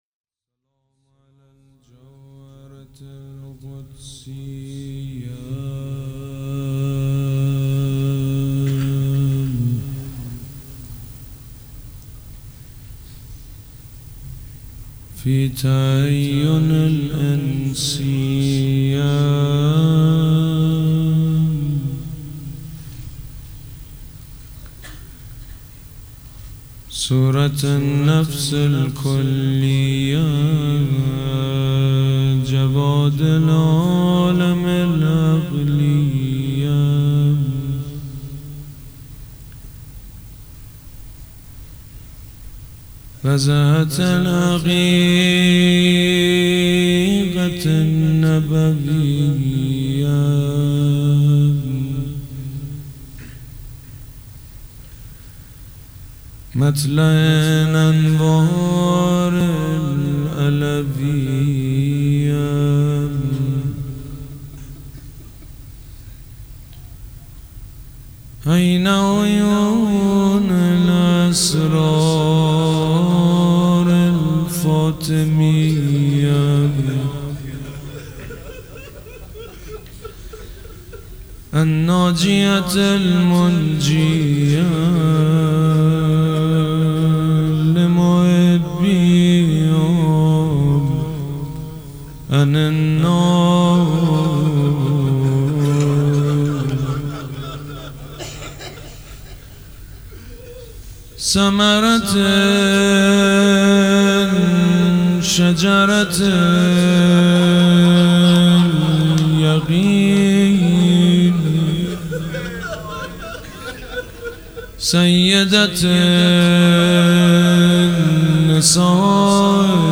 روضه بخش اول
شب دوم فاطمیه ۹۷
سبک اثــر روضه
مداح حاج سید مجید بنی فاطمه